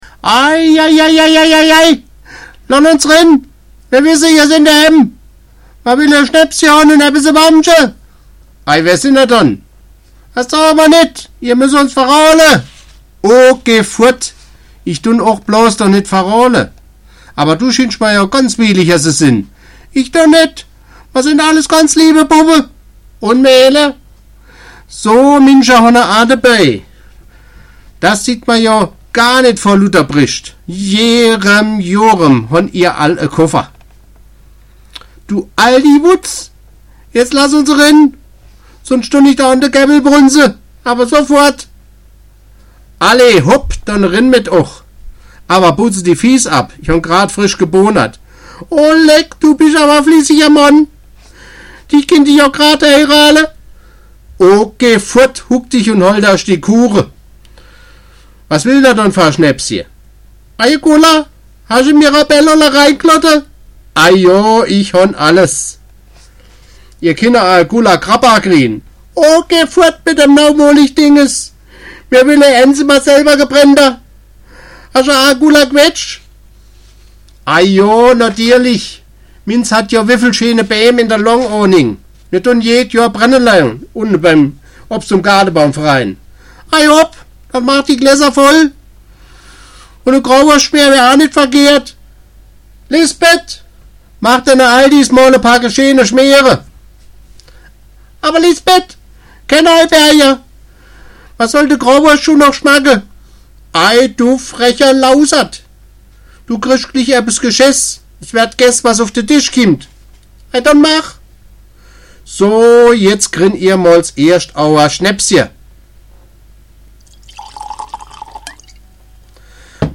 Aldi-Dialoge